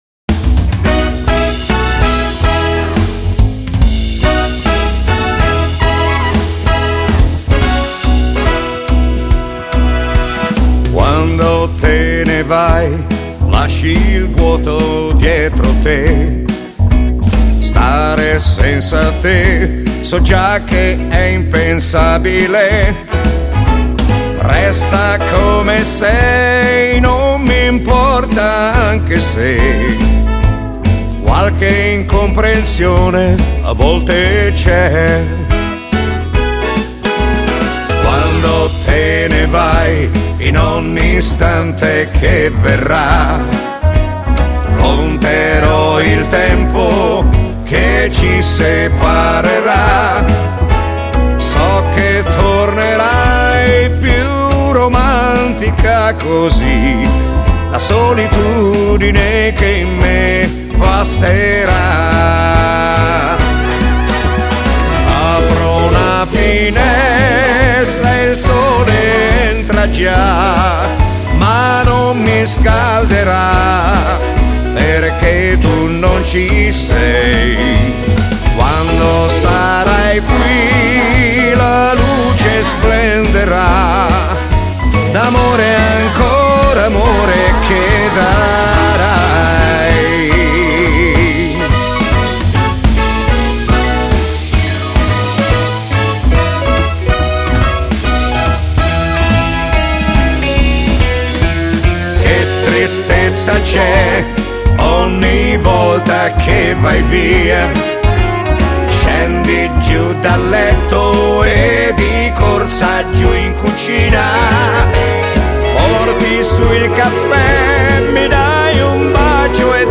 Genere: Swing - fox